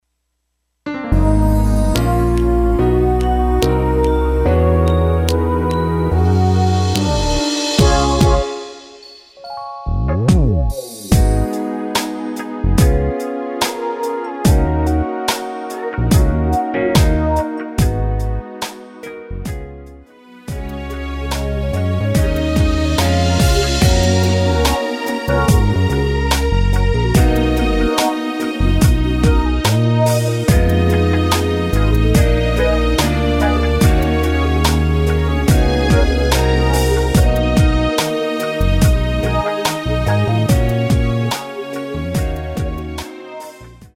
F#
앞부분30초, 뒷부분30초씩 편집해서 올려 드리고 있습니다.
중간에 음이 끈어지고 다시 나오는 이유는